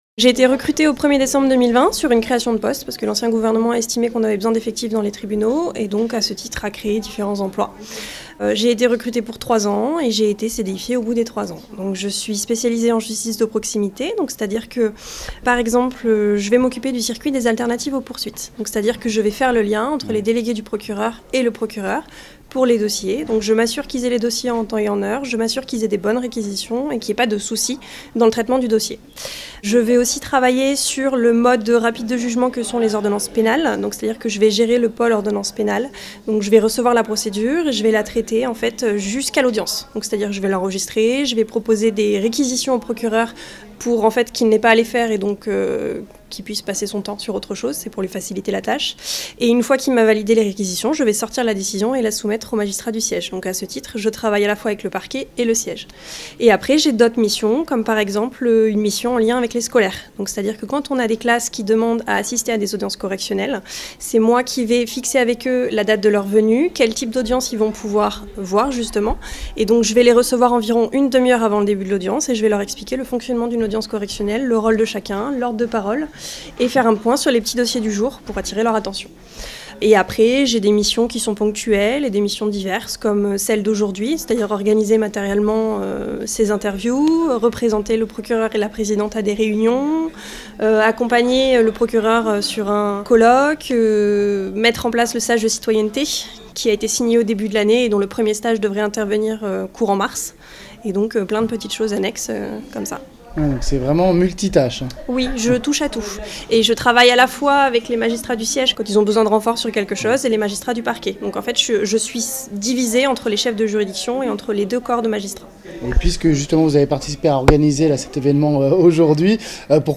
Dernier témoignage de femmes de la justice locale avec la chargée de mission